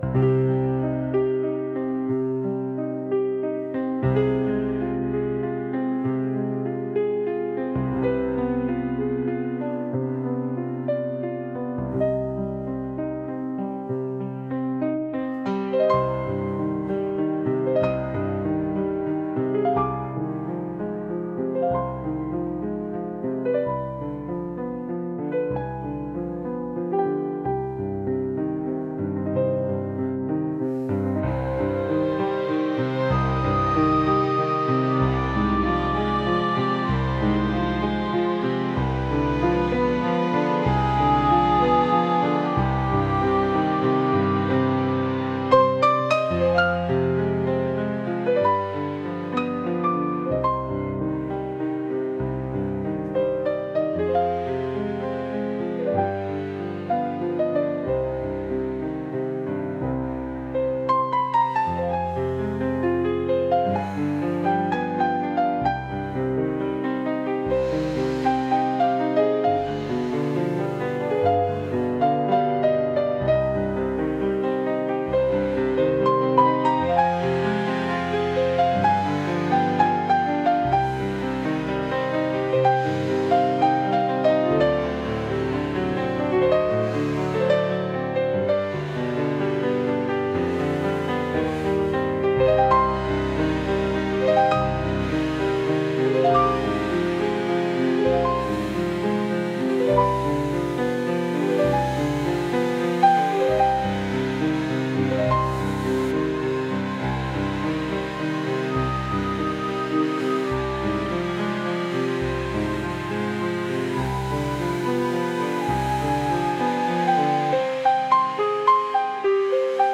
Movie, Romantic, Soul, Instrumental, Folk | 04.04.2025 17:16